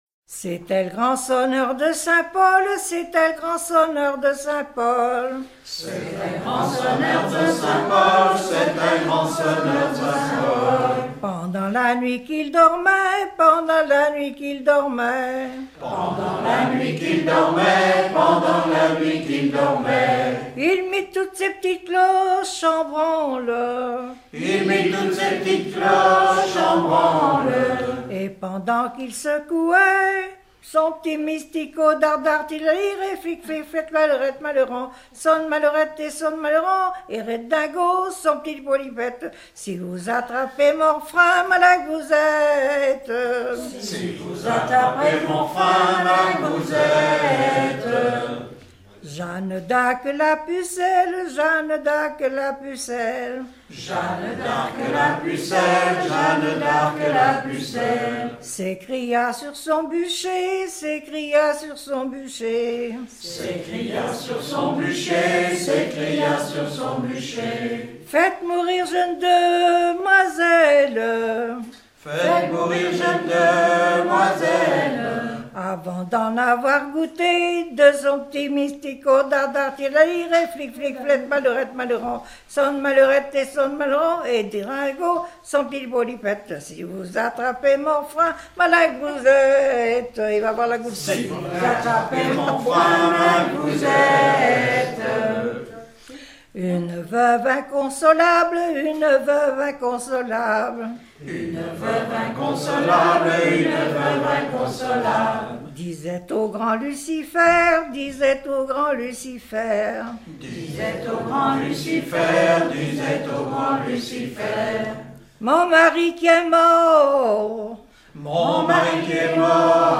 Bocage vendéen
Genre strophique
Pièce musicale éditée